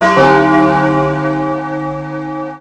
mvm_bomb_warning.wav